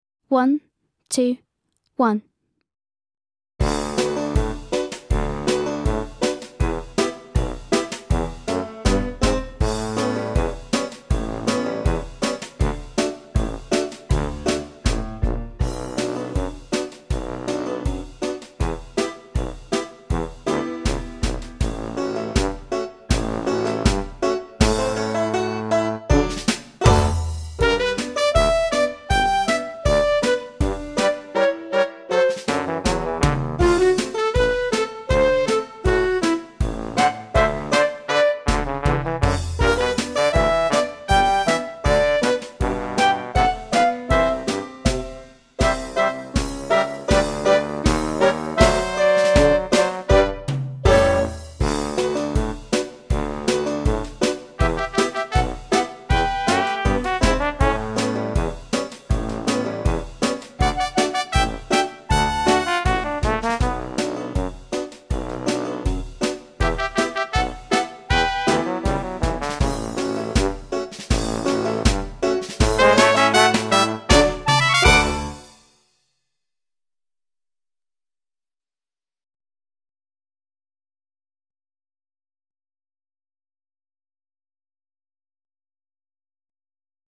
Hen-Coop Rag (Backing Track)